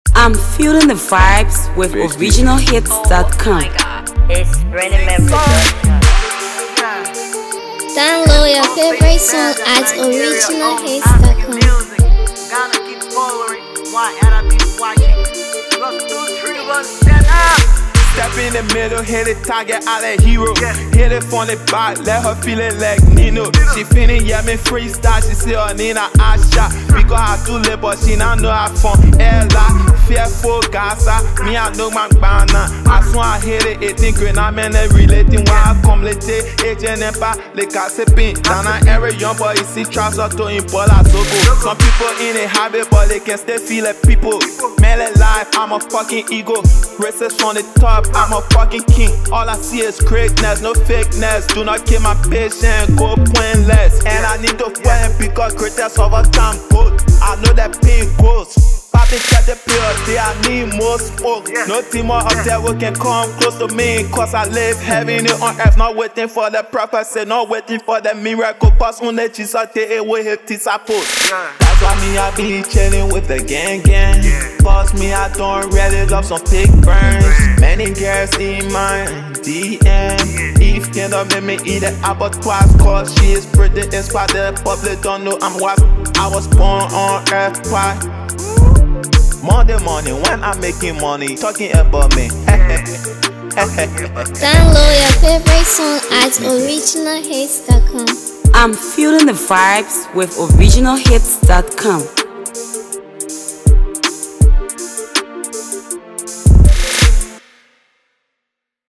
Hipco